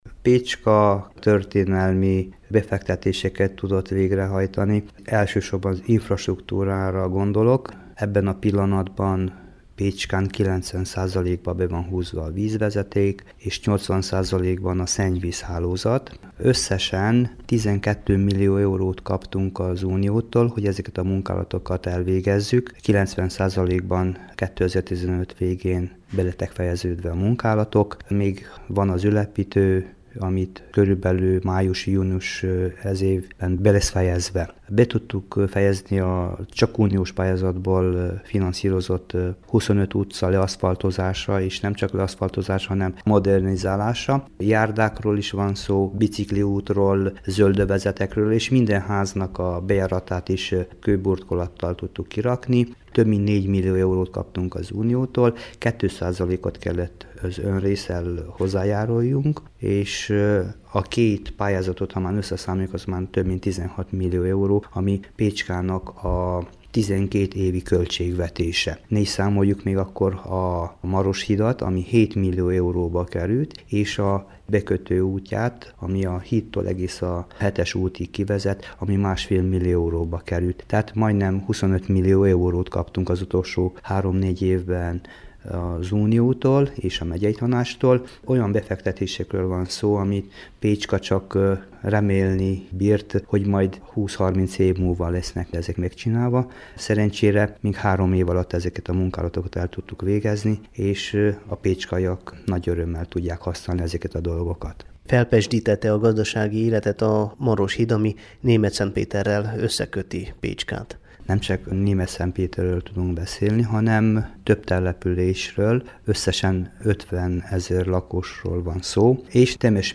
A tavaly átadott autópálya rövid távon kiesését jelent egyes vállalkozásoknak, de hosszútávon a kisváros előnyére válik majd a gyorsforgalmi út – vallja Antal Péter polgármester.
antal_peter_pecskai_tervek_2016.mp3